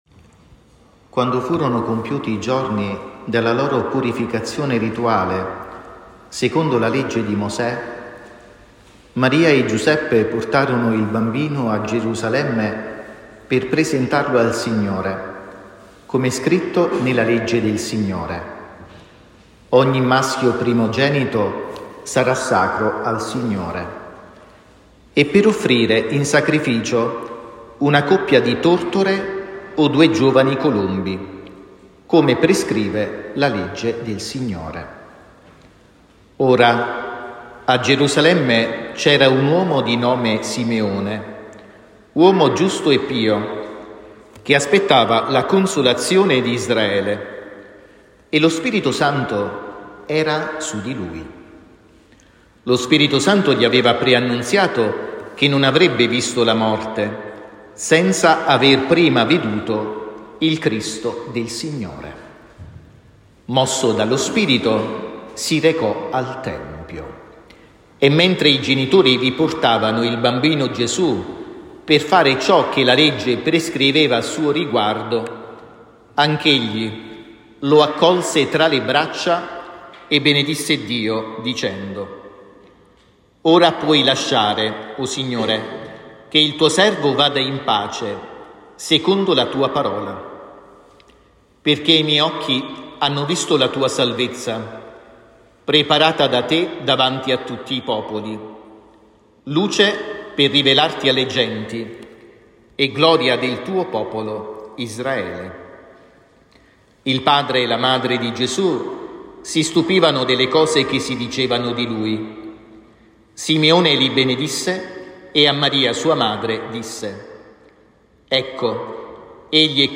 A Gerusalemme per presentarlo al Signore. Omelia